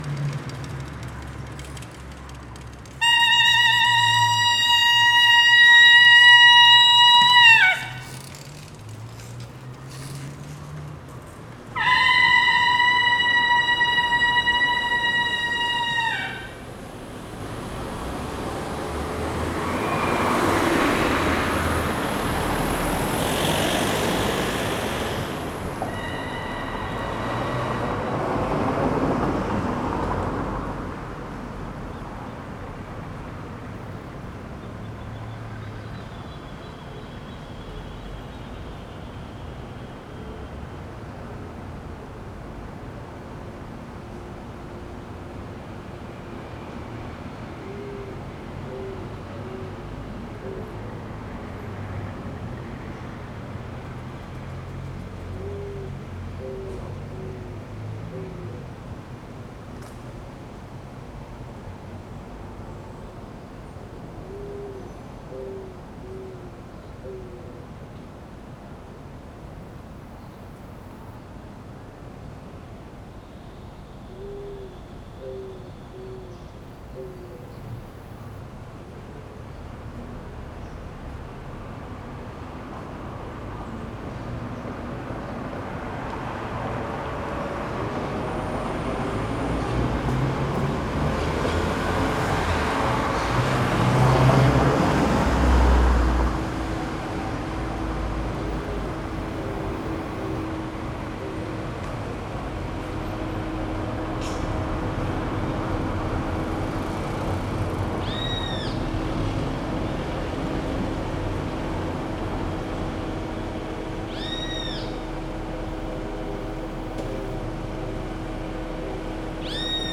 Paisajes Sonoros de Rosario